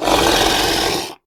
revenant.ogg